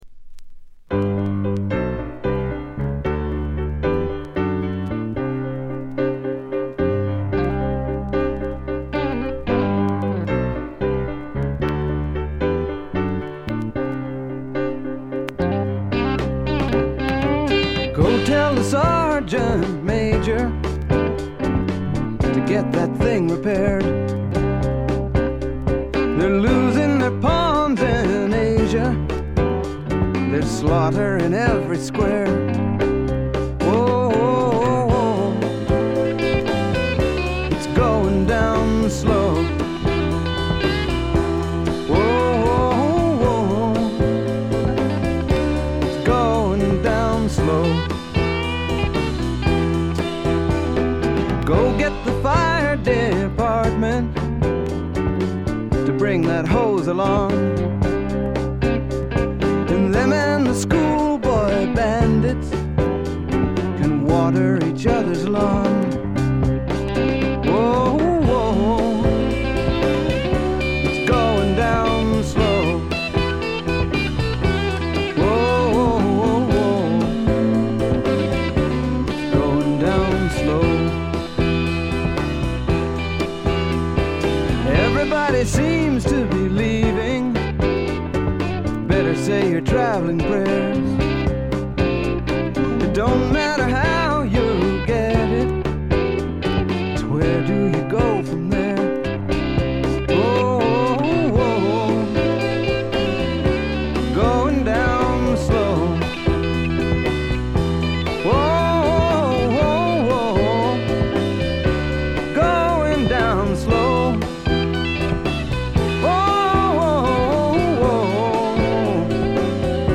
散発的なプツ音少し。
試聴曲は現品からの取り込み音源です。